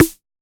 Index of /musicradar/retro-drum-machine-samples/Drums Hits/Tape Path A
RDM_TapeA_MT40-Snr02.wav